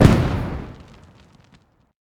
poison-capsule-explosion-3.ogg